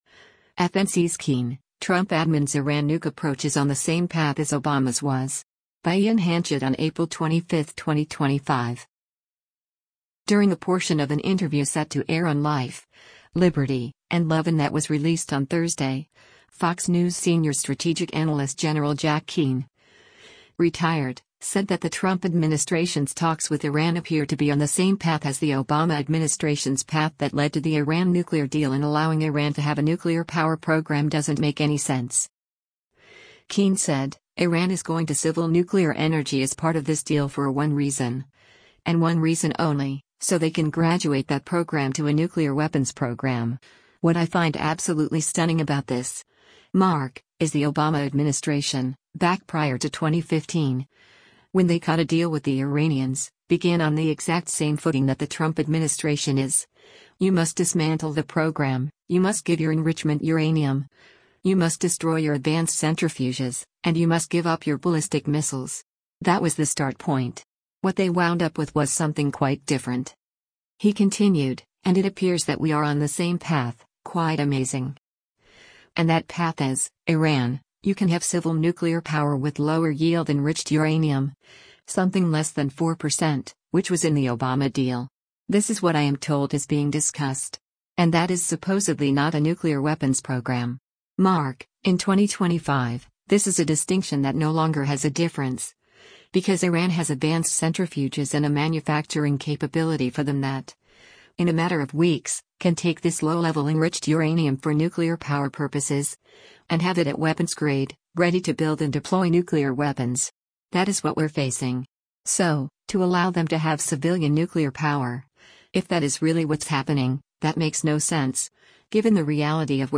During a portion of an interview set to air on “Life, Liberty, and Levin” that was released on Thursday, Fox News Senior Strategic Analyst Gen. Jack Keane (Ret.) said that the Trump administration’s talks with Iran appear to be on the same path as the Obama administration’s path that led to the Iran nuclear deal and allowing Iran to have a nuclear power program doesn’t make any sense.